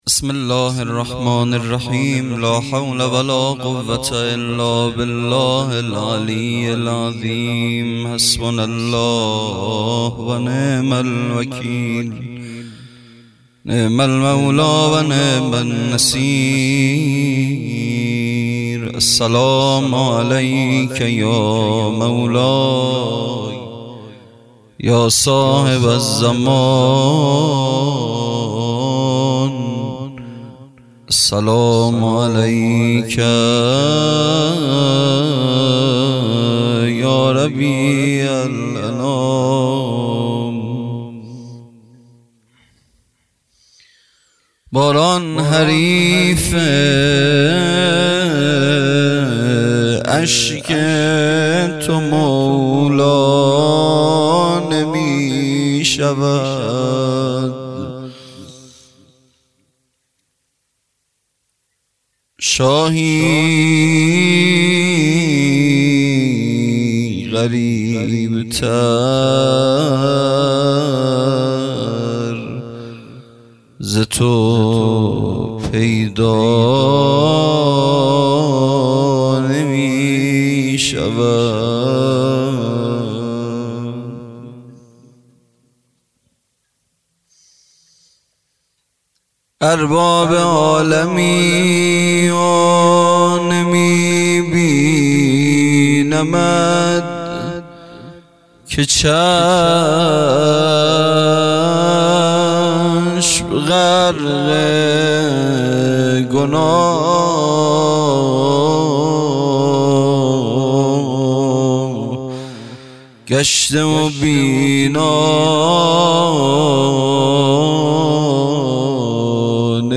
خیمه گاه - هیئت بچه های فاطمه (س) - سخنرانی | پنج شنبه ۱ مهر ۱۴۰۰
جلسه‌ هفتگی | اربعین